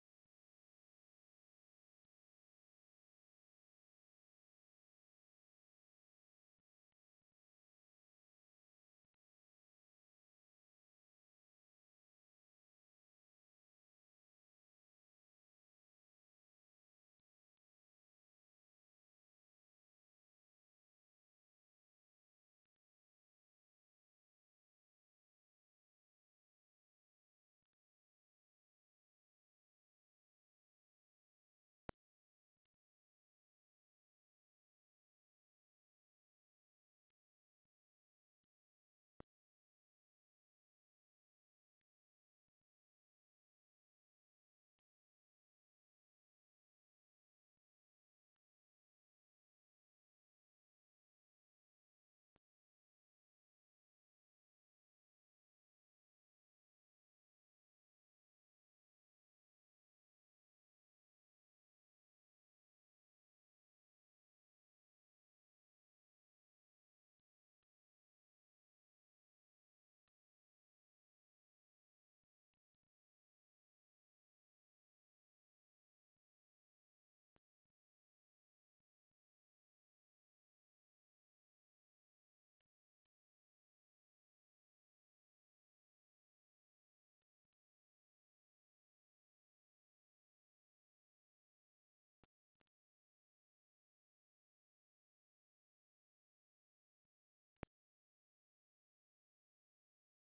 我已将 CC2564连接到 WL1833并记录了 WL1833的输出。 随附的 wav 文件表明、实际上、我们在接收端获得了一些垃圾数据。
此处听到了示波器爆裂声
如果我通过 I2S 线路播放任何语音或音乐、音频在接收端听起来不错、偶尔会有人在接收端弹出。
recordedPops.wav